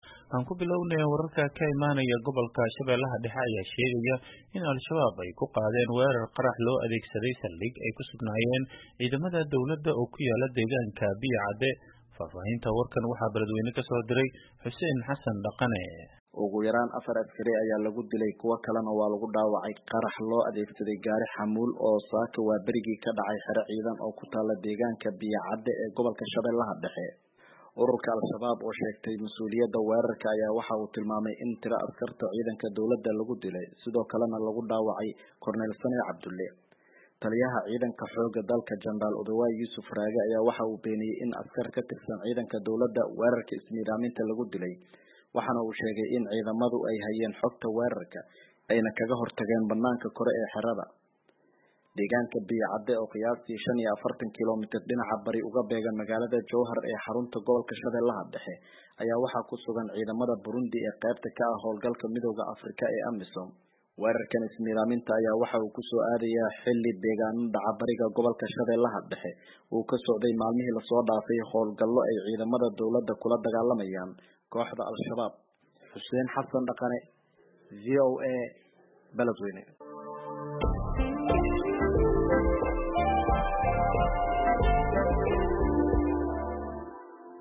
Dhageyso warbixinta Sh. Dhexe